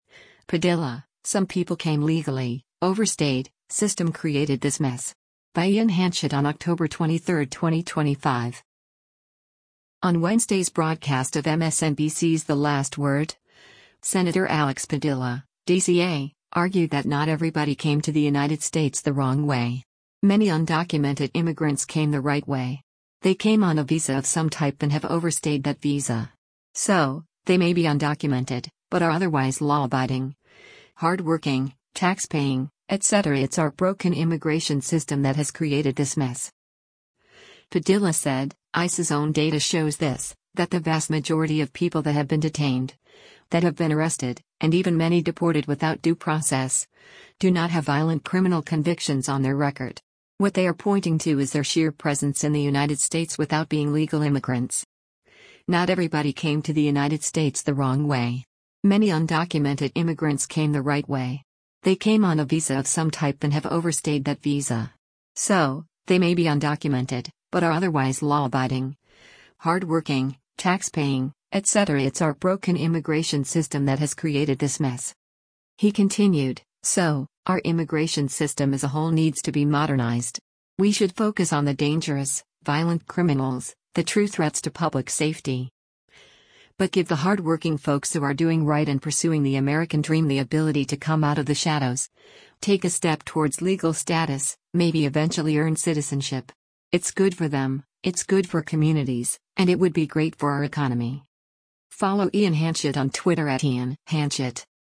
On Wednesday’s broadcast of MSNBC’s “The Last Word,” Sen. Alex Padilla (D-CA) argued that “Not everybody came to the United States the wrong way. Many undocumented immigrants came the right way. They came on a visa of some type and have overstayed that visa. So, they may be undocumented, but are otherwise law-abiding, hard-working, taxpaying, etc. It’s our broken immigration system that has created this mess.”